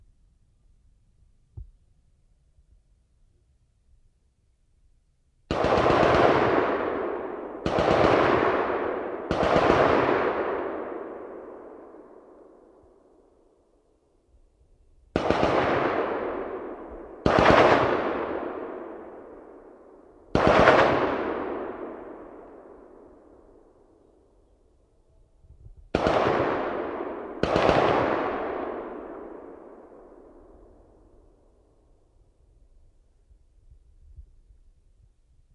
它是苏格兰第二次世界大战的储油罐。使用1/4“测量麦克风和起动手枪测量脉冲响应。